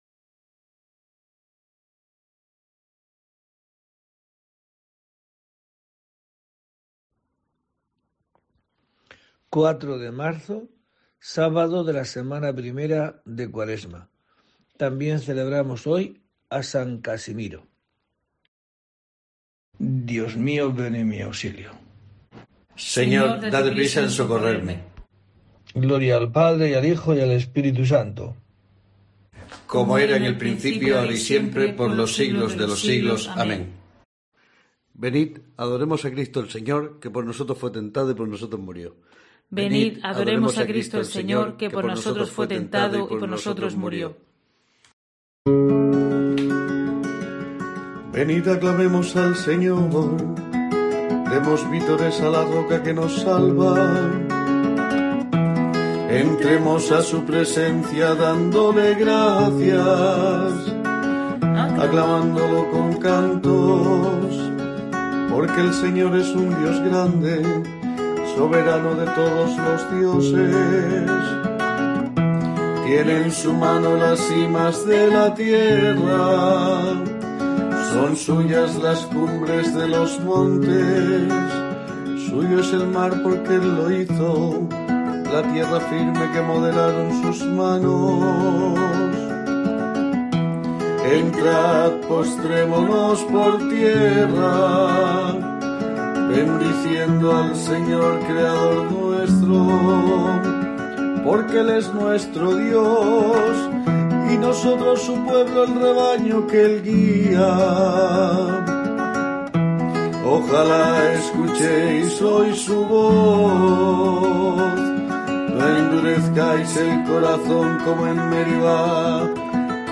4 de marzo: COPE te trae el rezo diario de los Laudes para acompañarte